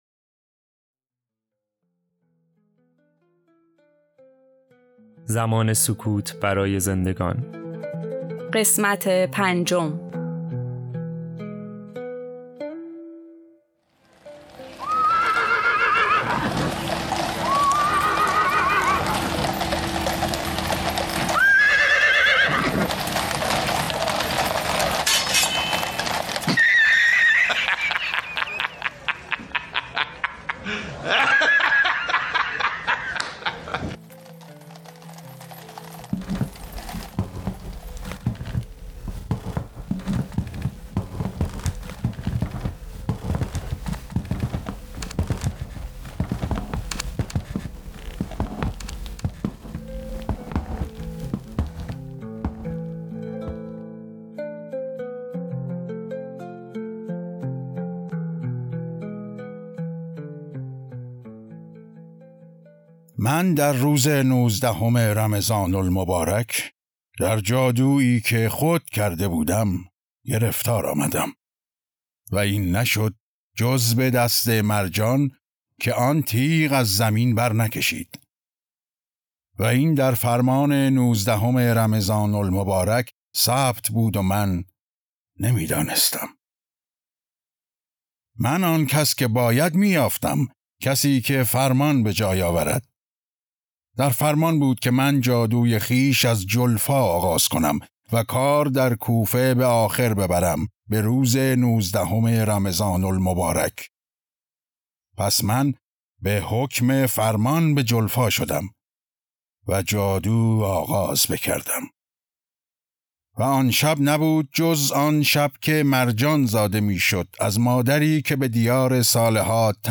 پادکست | نمایش رادیویی زمان سکوت برای زندگان
"زمان سکوت برای زندگان" روایت‌ روزهای ضربت‌ خوردن حضرت علی(ع) است که از زبان چندشخصیت فرعی روایت می‌شود. این نمایشنامه توسط چند هنرمند تئاتر مشهد به‌صورت اختصاصی برای مخاطبان شهرآرانیوز اجرا شده است.